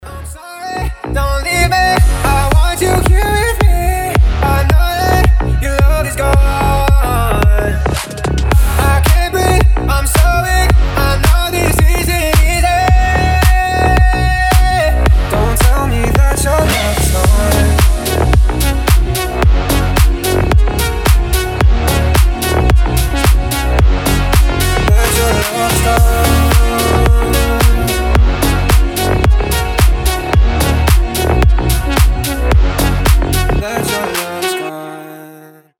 • Качество: 320, Stereo
красивый мужской голос
Electronic
EDM
ремиксы
slap house